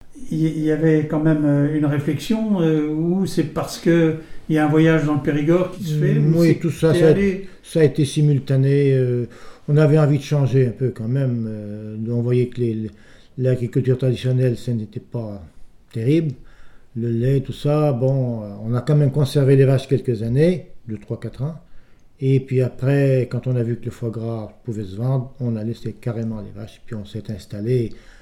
Il provient de Saint-Gervais.
Témoignage ( mémoire, activité,... )